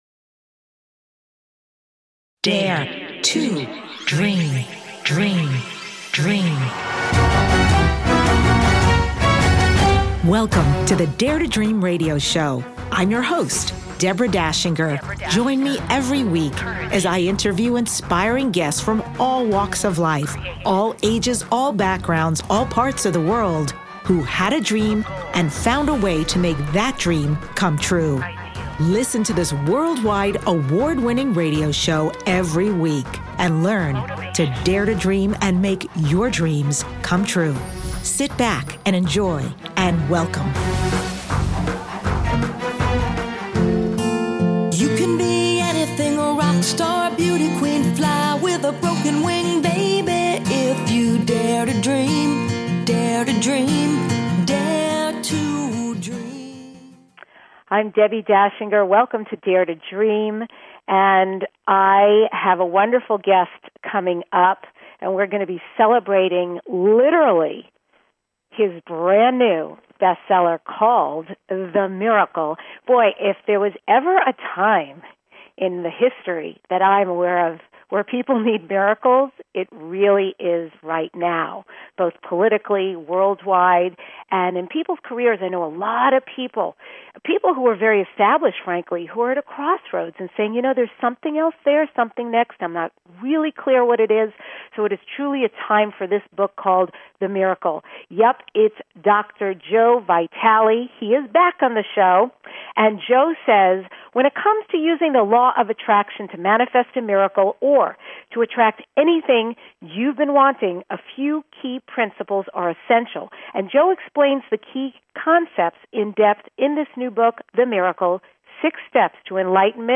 Guest, Dr. Joe Vitale